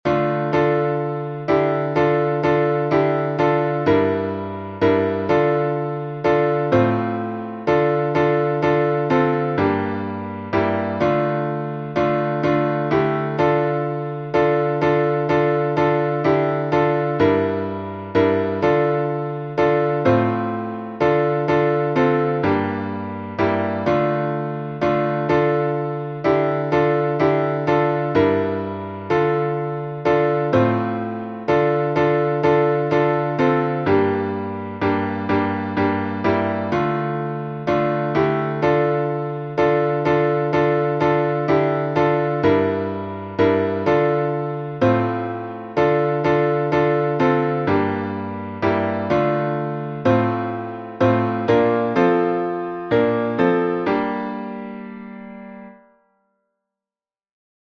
Киевский распев, глас 7